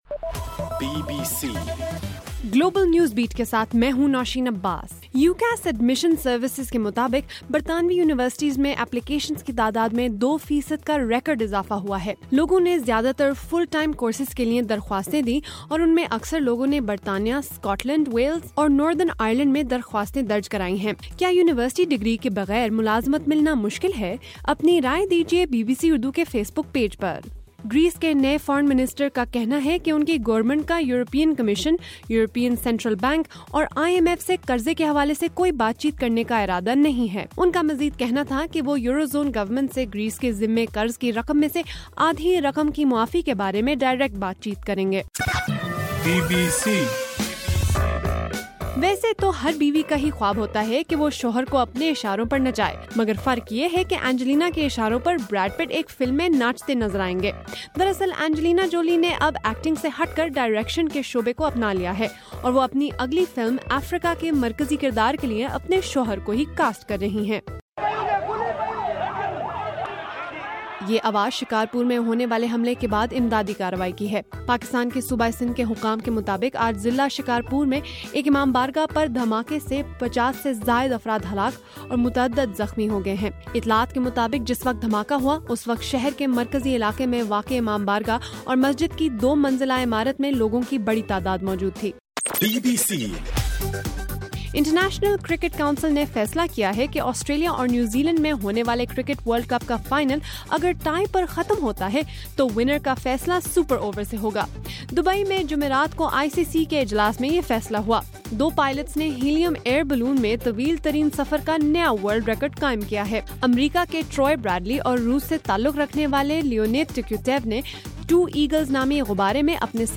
جنوری 30: رات 12 بجے کا گلوبل نیوز بیٹ بُلیٹن